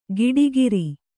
♪ giḍigiri